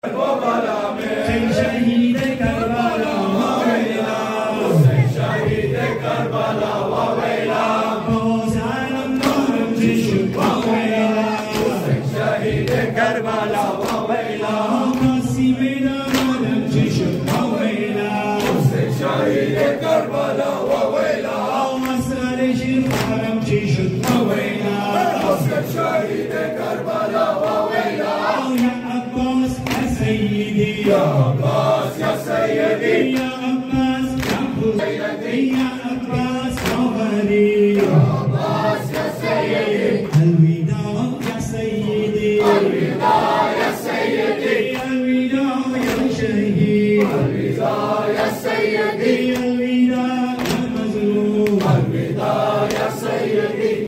Ending Matams